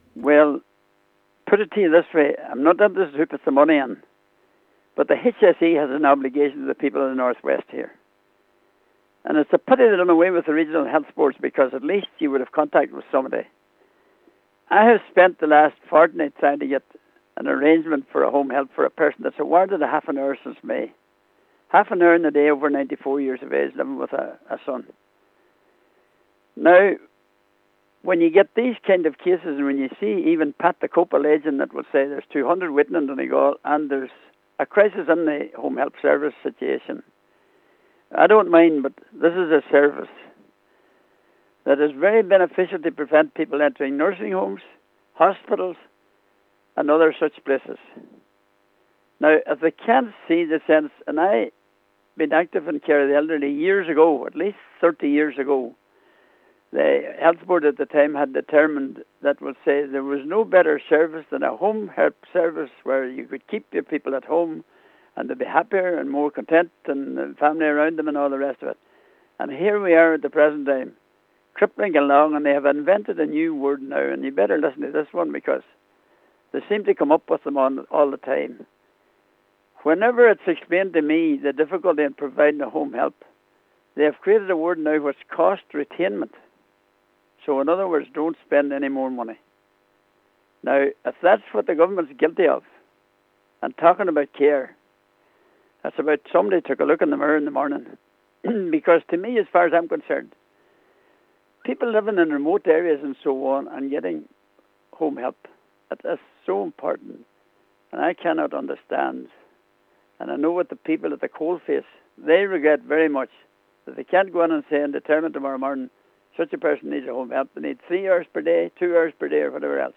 Local Cllr Ian McGarvey says neither the delays nor the lack of hours awarded to each individual make any sense: